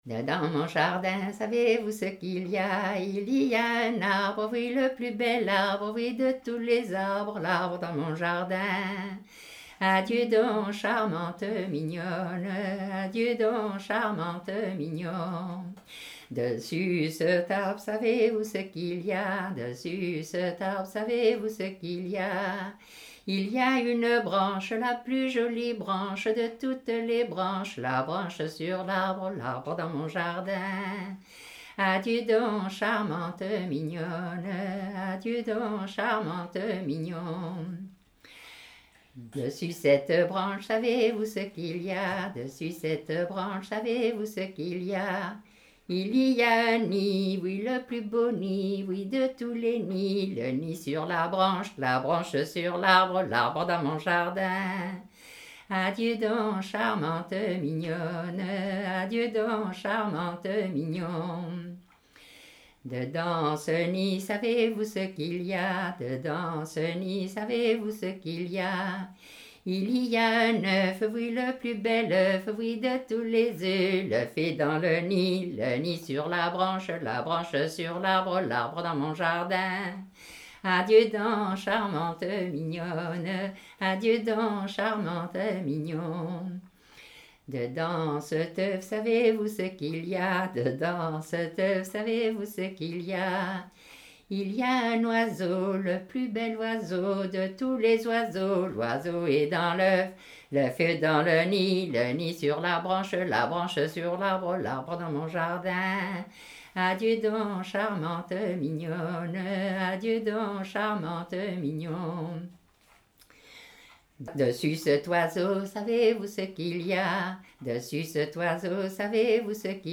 Musique à danser